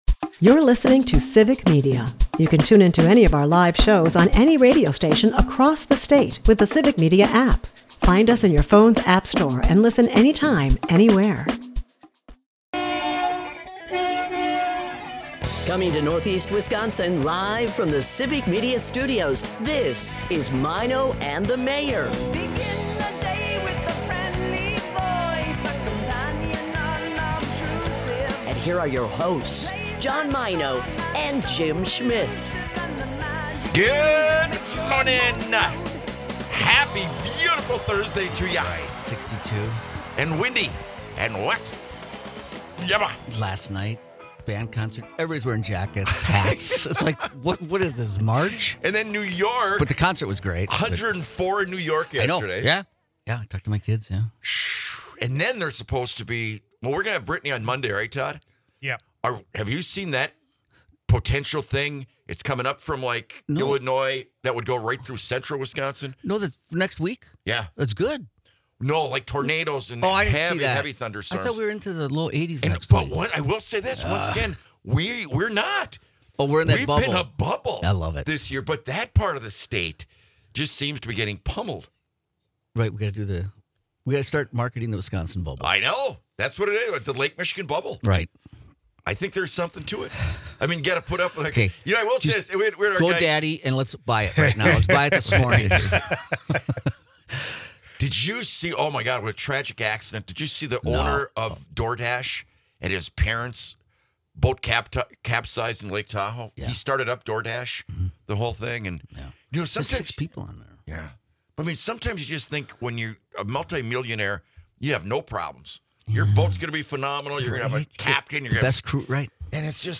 The guys start the morning off talking about the crazy weather patterns we've seen during the month of June, from chilly band concerts to the Wisconsin bubble phenomena. They muse on Pope visits to Green Bay, envisioning grand spectacles and economic boons, all while evoking local pride and humor. The podcast also covers the NBA draft excitement, particularly focusing on the fortunes of a young Wisconsin athlete, Kon Knueppel. Listeners chime in on ideas for a new game show: "Are You Catholic?".